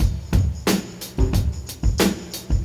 • 90 Bpm Drum Groove G Key.wav
Free drum beat - kick tuned to the G note. Loudest frequency: 1077Hz
90-bpm-drum-groove-g-key-Roe.wav